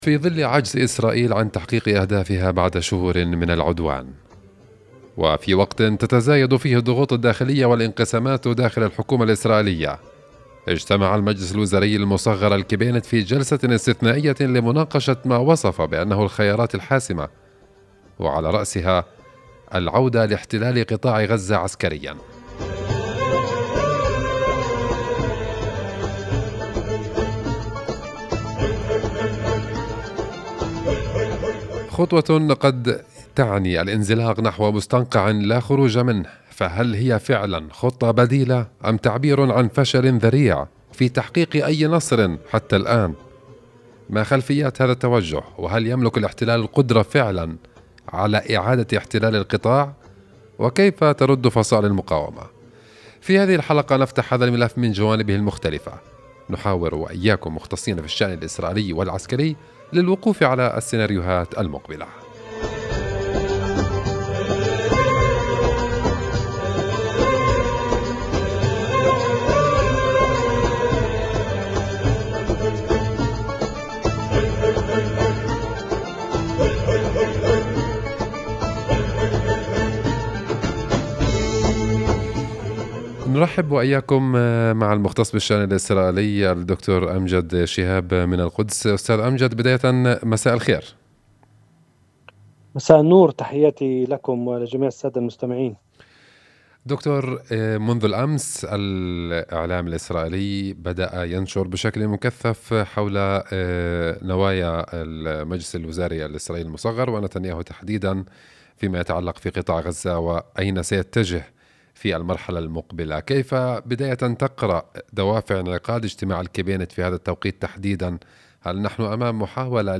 وفي حديث لإذاعة “القدس”